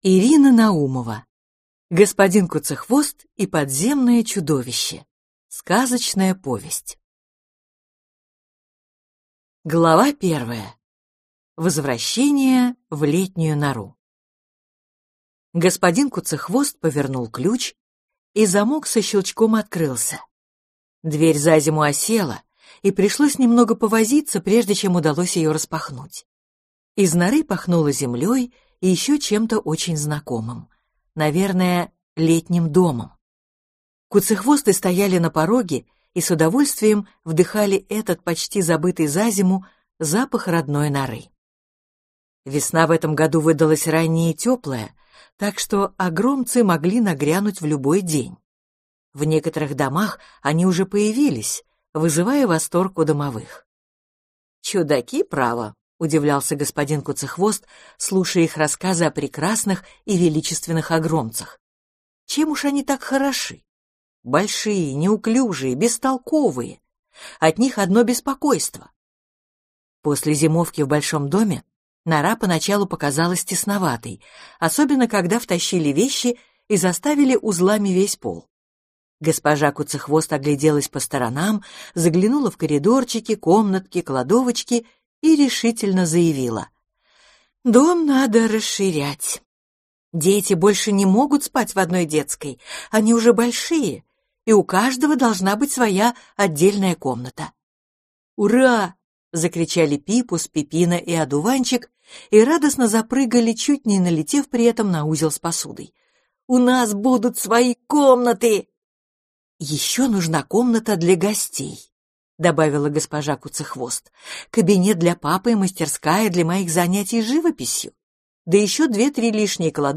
Аудиокнига Господин Куцехвост и подземное чудовище | Библиотека аудиокниг